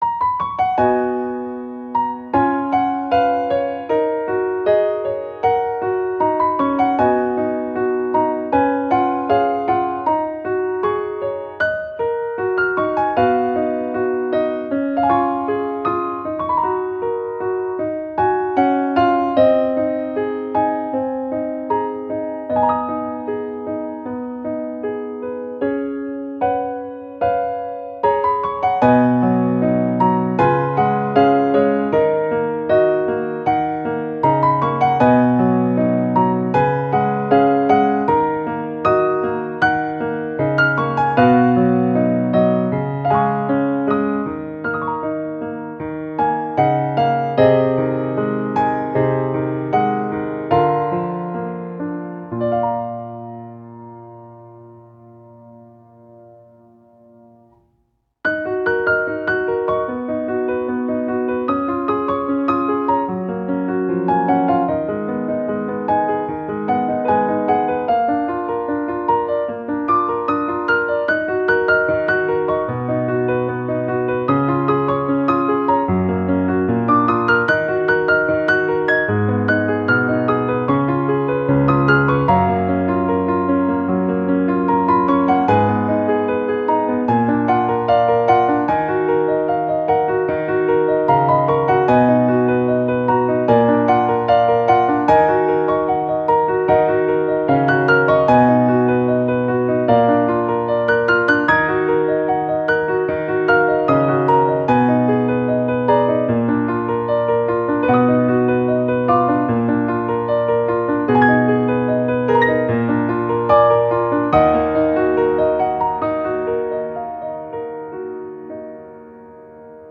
-oggをループ化-   切ない しっとり 2:06 mp3